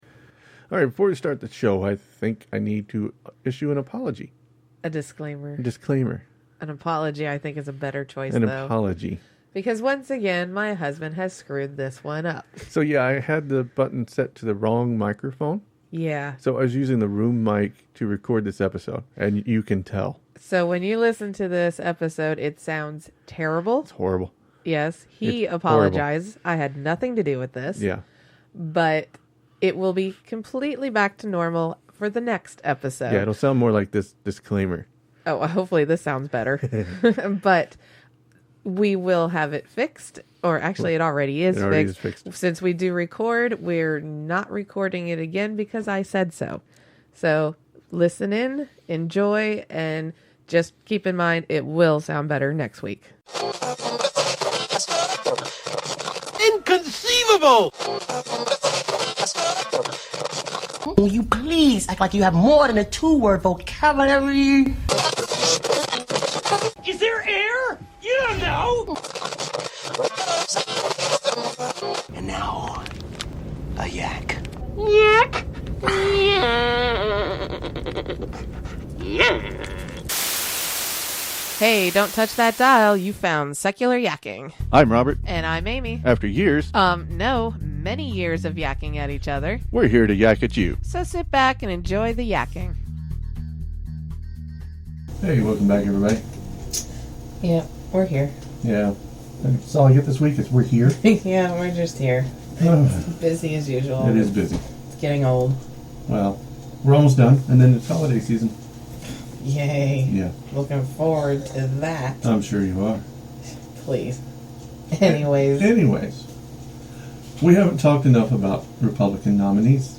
I deeply apologize about the sound quality.